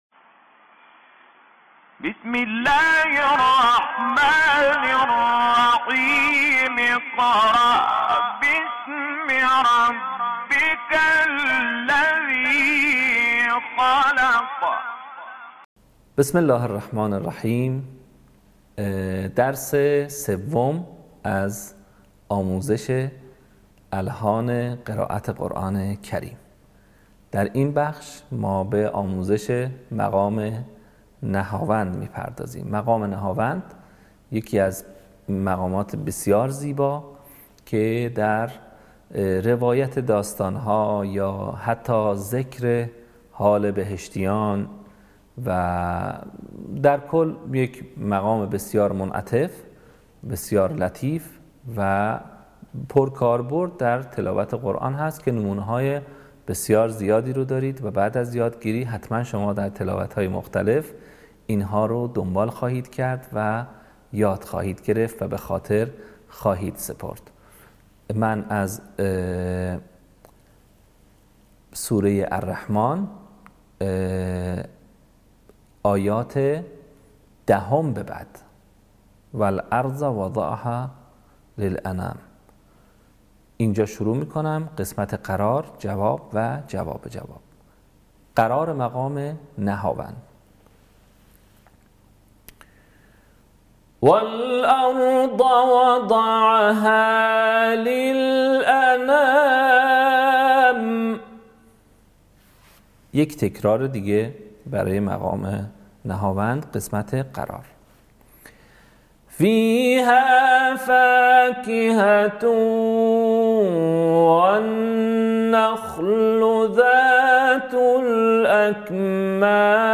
صوت | آموزش مقام نهاوند